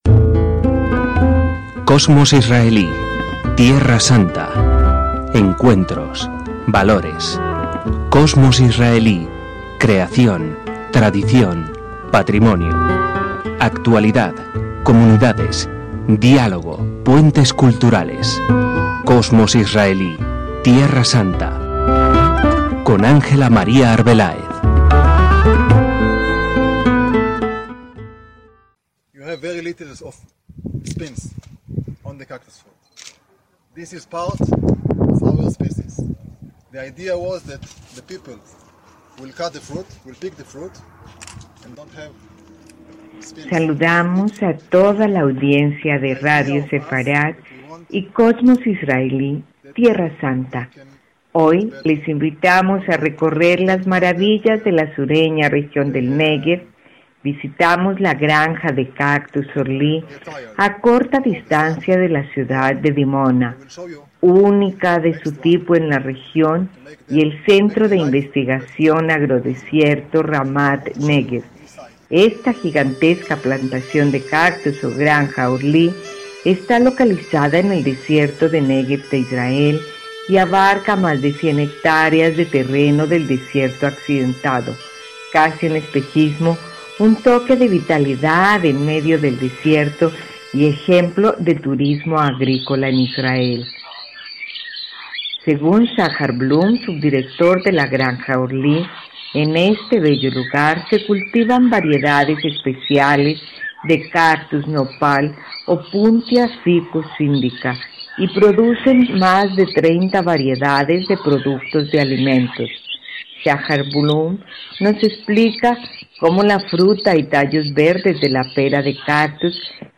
De la plantación de cactus continuamos nuestro recorrido por el Centro de Investigaciones Agrícolas Ramat Negev I + D. Segunda parte de la entrevista a Rodrigo X. Carreras, Embajador de Costa Rica en